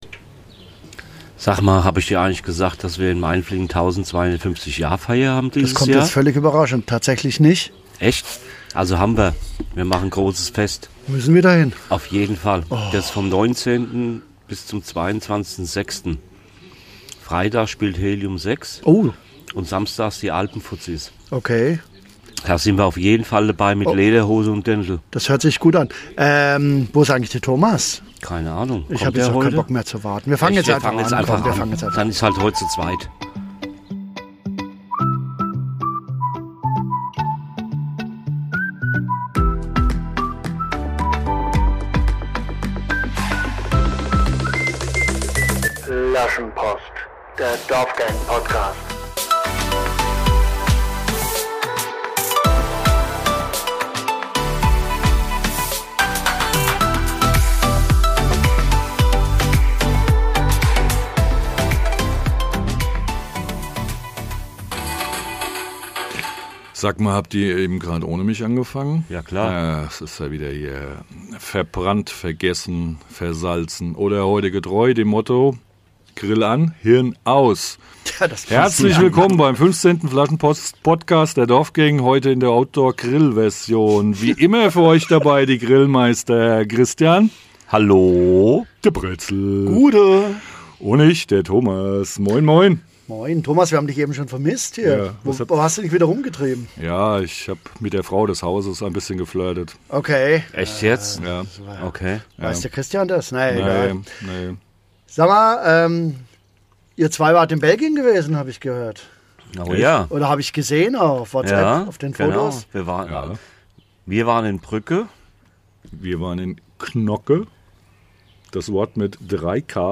Unter der Pergola bei wildem Vogelgezwitscher genießen wir das 3 Gänge Grillmenü und trinken unterschiedlichste Weizenbiere aus Süddeutschland und Schnäpseln Wildkirschlikör.